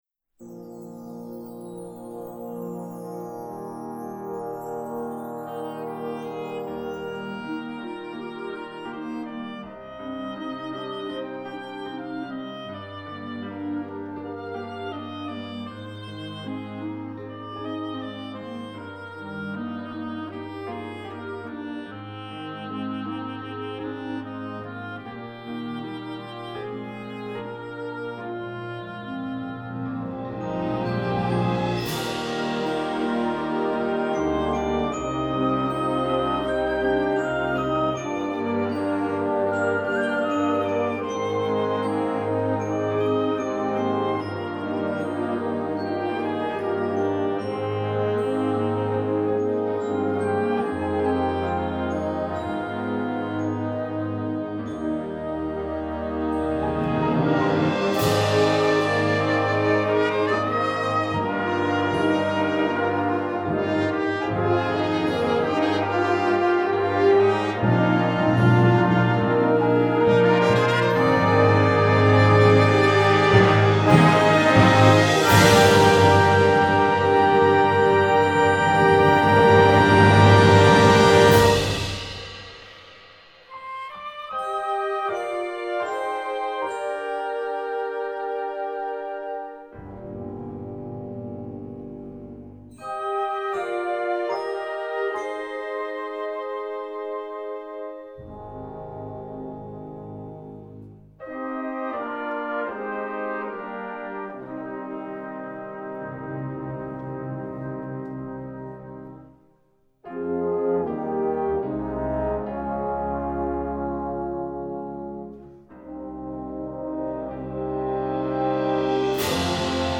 Instrumentation: concert band
instructional